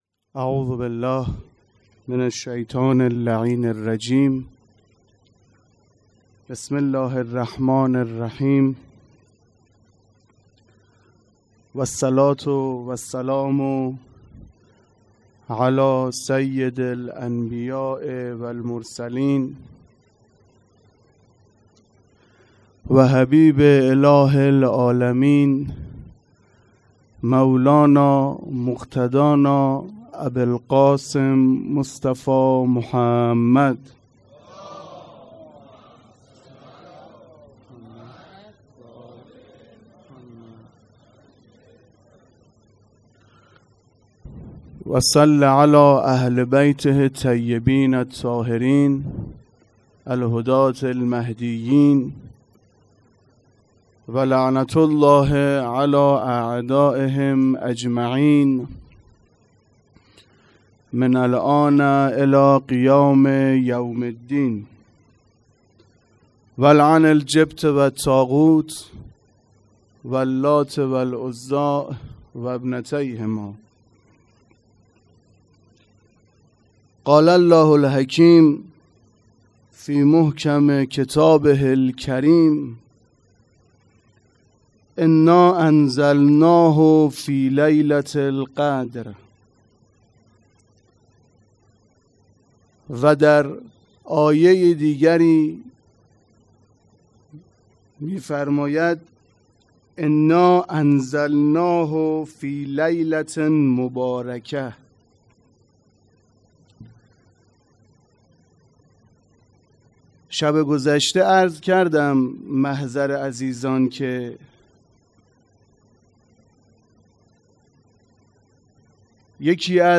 سخنرانی
مراسم عزاداری محرم ۱۴۰۴